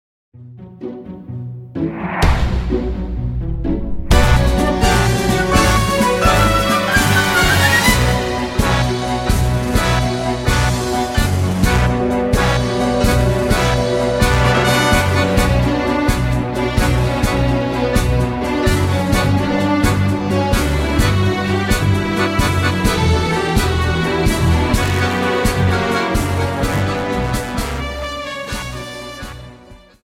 Dance: Tango